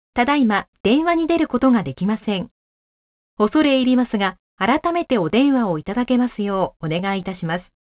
■アナウンスサービス２